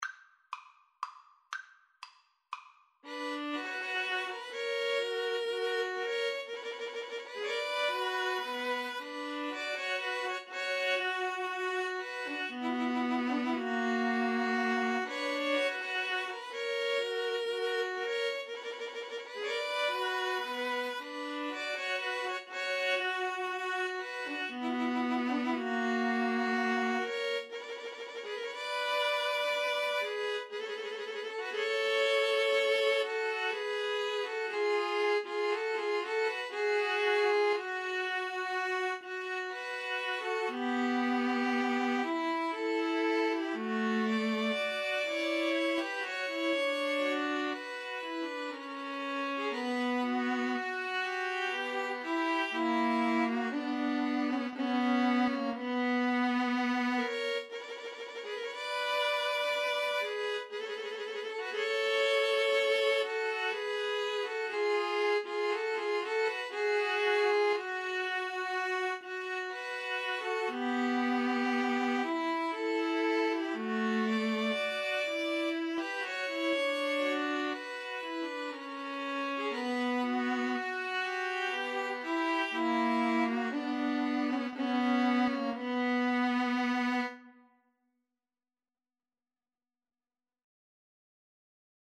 Vivace (View more music marked Vivace)
String trio  (View more Intermediate String trio Music)
Classical (View more Classical String trio Music)